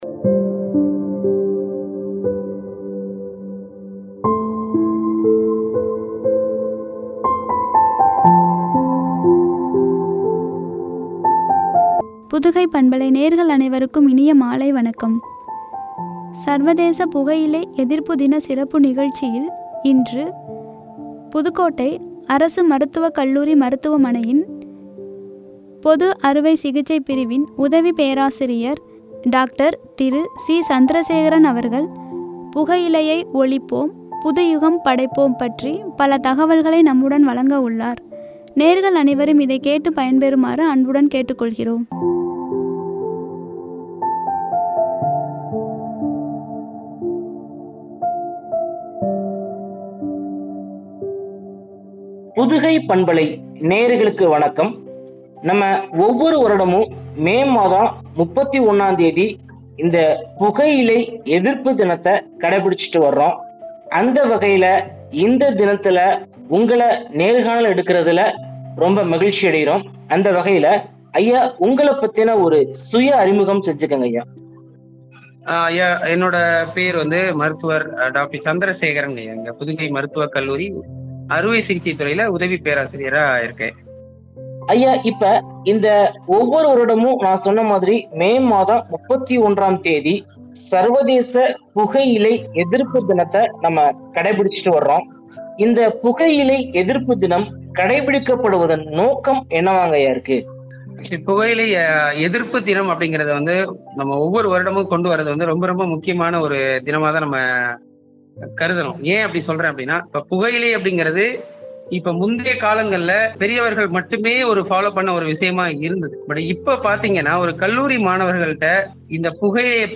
புதுயுகம் படைப்போம்” குறித்து வழங்கிய உரையாடல்.